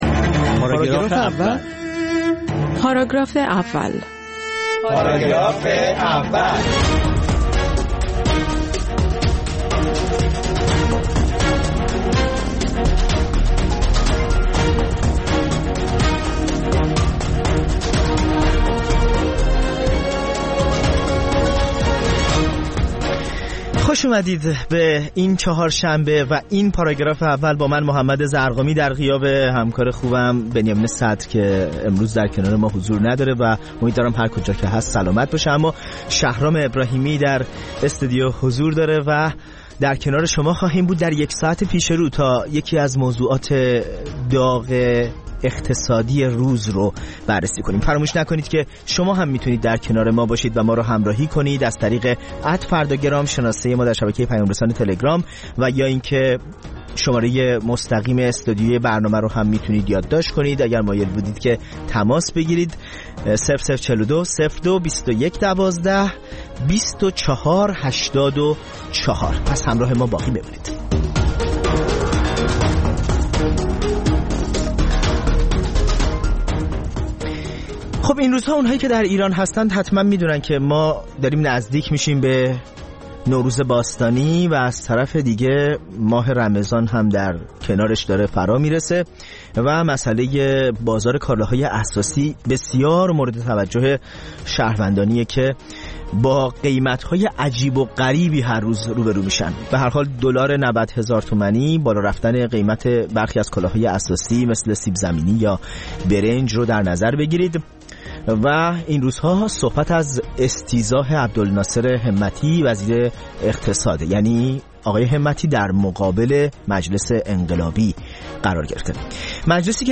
فروپاشی اقتصادی، استیضاح همتی و «پایداری» خامنه‌ای در میزگرد «پاراگراف اول»